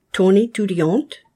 When one word ends in an “n” or “s” and is followed by a word that starts with a vowel sound, you must pronounce the “n” as itself and the “s” as a “z”.
Click on each of the following examples of liaison, and repeat the proper pronunciation after the speaker.
ton_etudiante.mp3